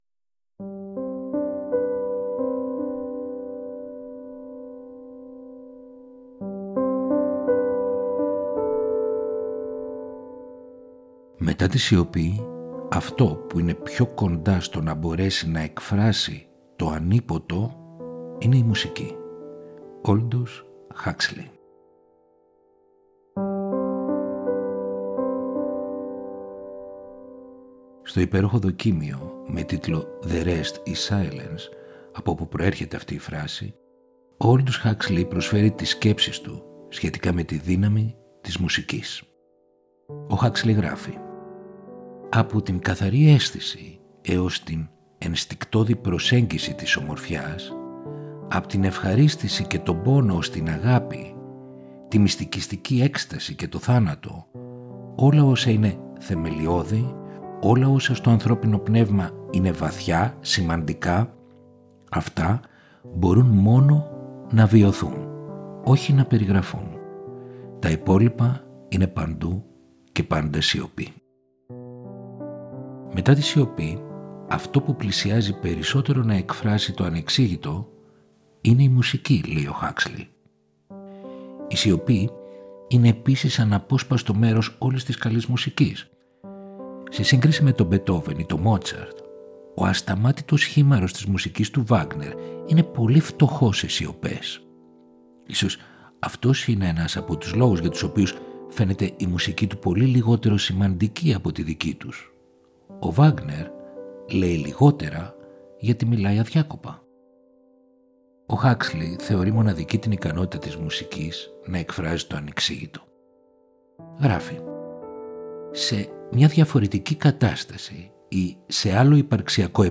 φωνητικού συνόλου